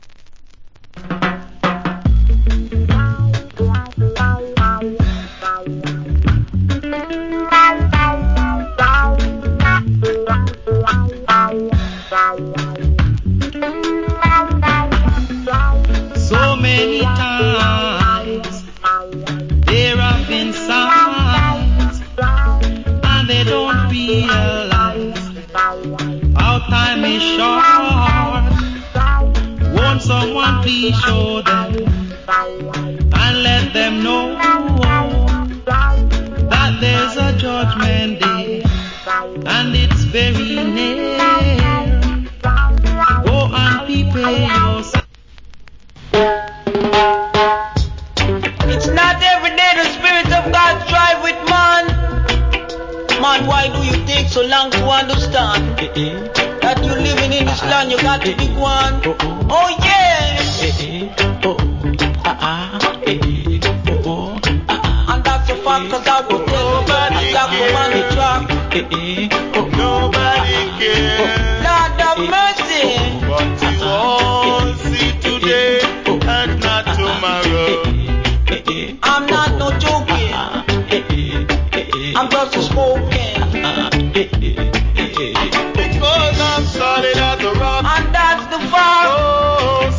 Cool Roots Rock Vocal.